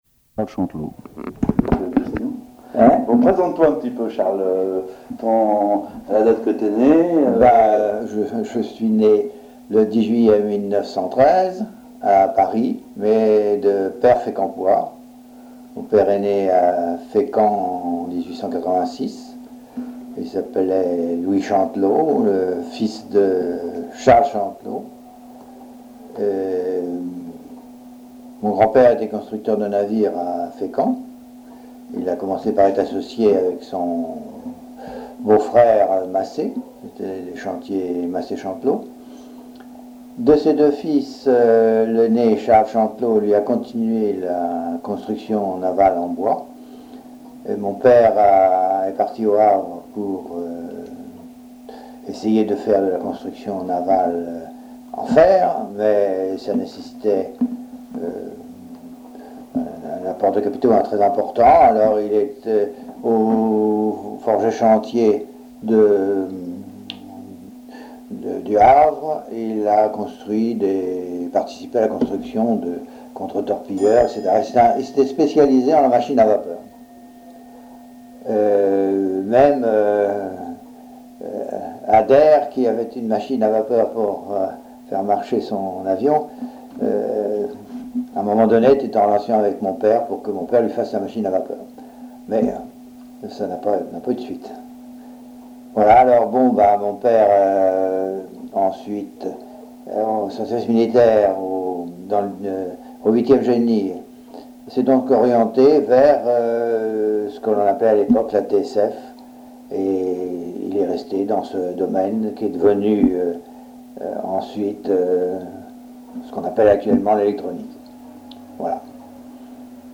Témoignages sur la construction navale à Fécamp
Catégorie Témoignage